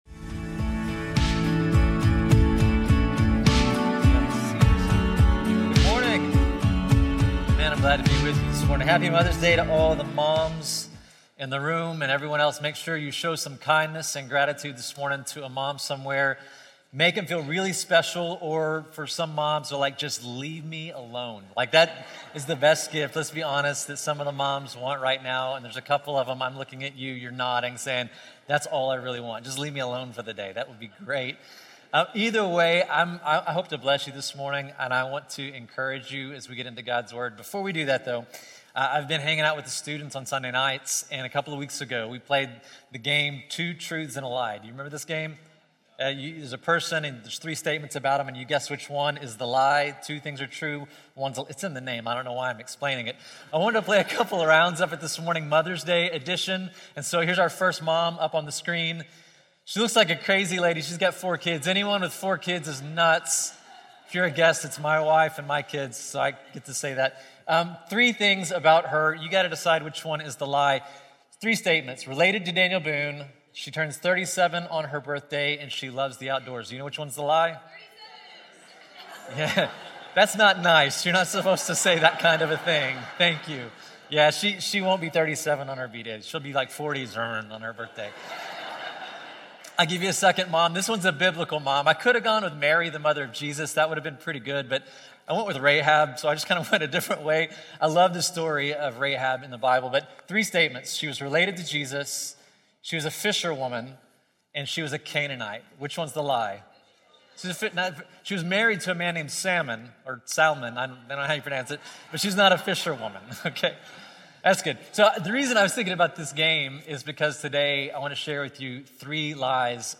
In this heartfelt Mother’s Day message, we dive into John 15 and unpack Jesus’ invitation to abide in Him. With warmth, humor, and honesty, the speaker begins by reflecting on the highs and exhaustion of motherhood, using a light-hearted “Two Truths and a Lie” game as an intro. From there, we move into deeper territory—examining the three common lies that many of us, especially moms, often believe: that knowing more, coasting through life, or going it alone is enough to create the life we desire.
The sermon offers real-world, practical guidance for cultivating a life of abiding: Scripture before screens, and prayer instead of anxious self-talk.